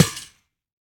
Monster_Spawner_break1_JE1_BE1.wav